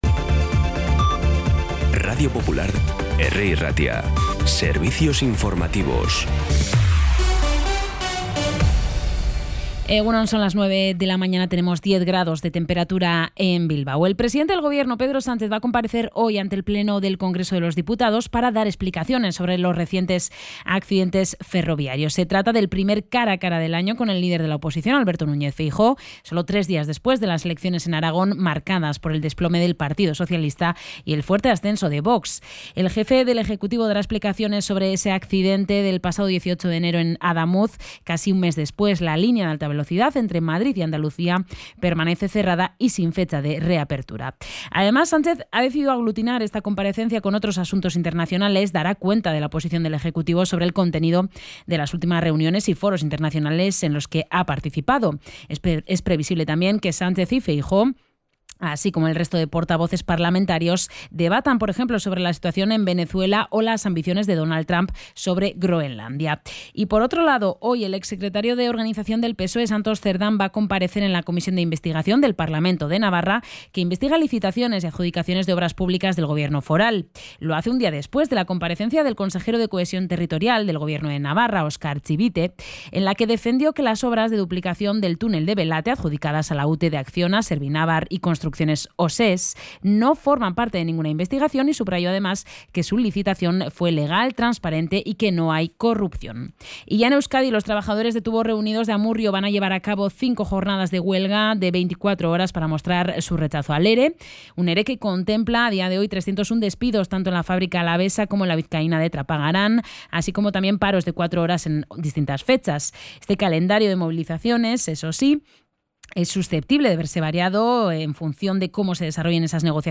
Las noticias de Bilbao y Bizkaia de las 9 , hoy 11 de febrero
Los titulares actualizados con las voces del día. Bilbao, Bizkaia, comarcas, política, sociedad, cultura, sucesos, información de servicio público.